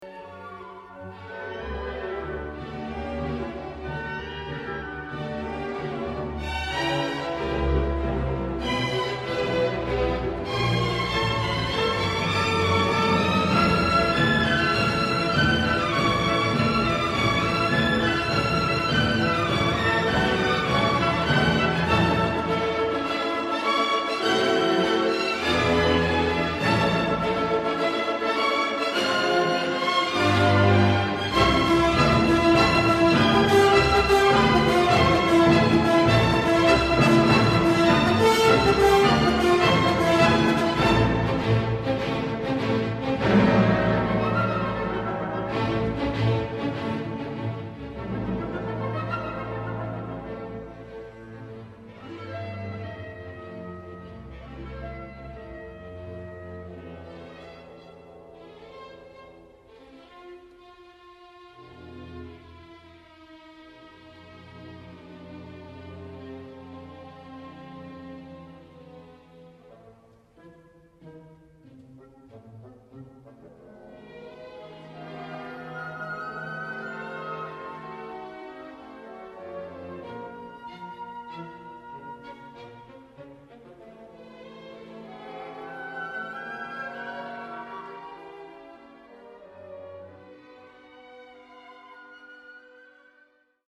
obra llena de vitalidad y alegría pero también de dolor